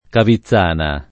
[ kavi ZZ# na ]